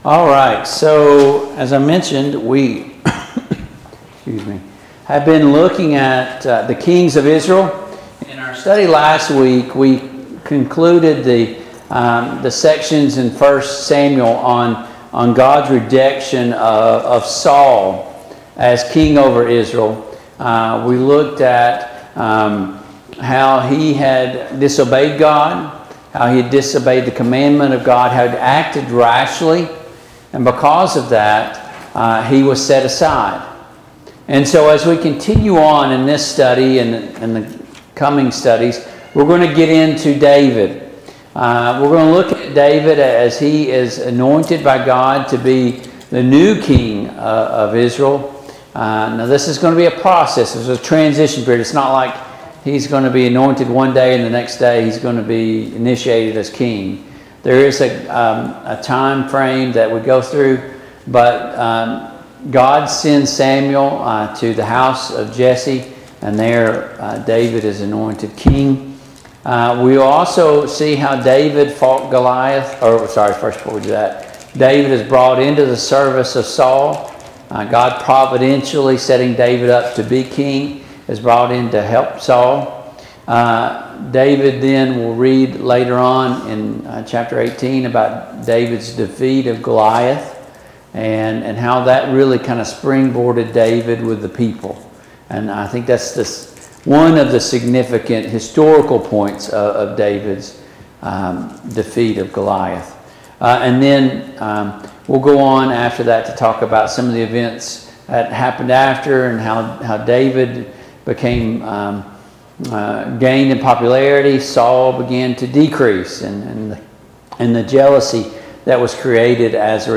Mid-Week Bible Study Download Files Notes « 2021 Ladies Day